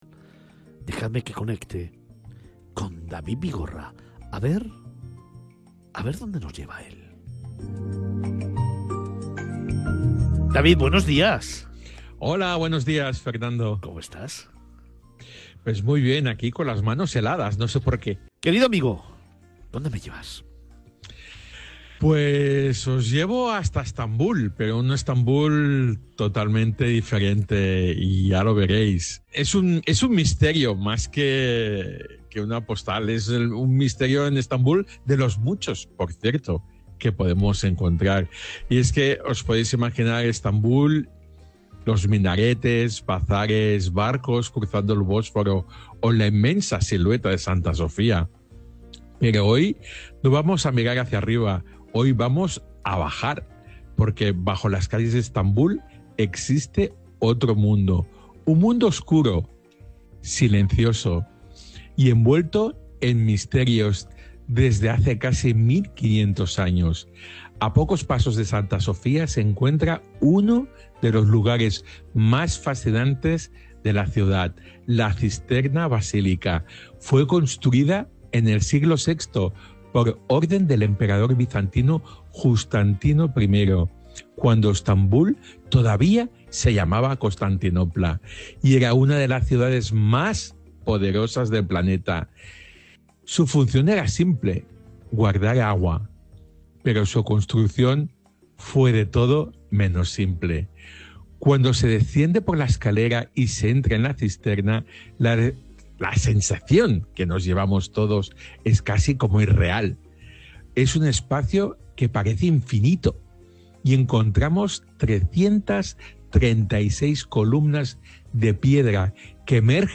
Miradas Viajeras se emite en Capital Radio, una emisora centrada en la información económica y empresarial.